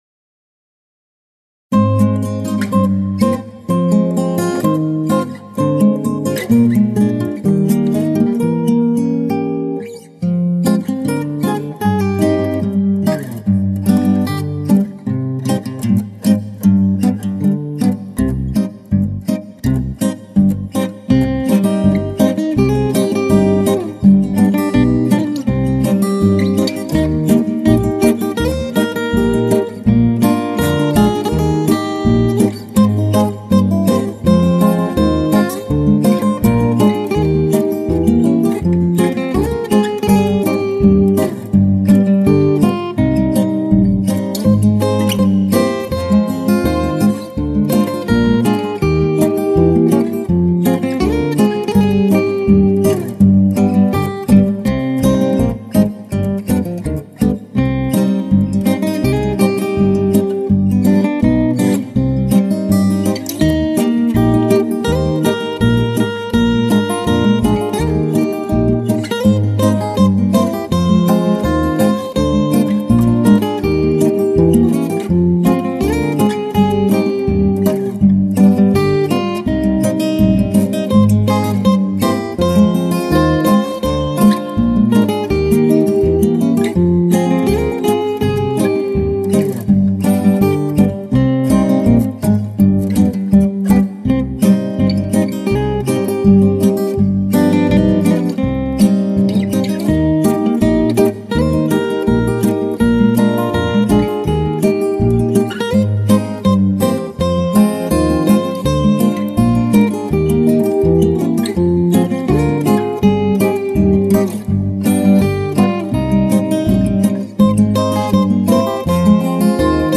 Послушай, как гитары популярную песню играют.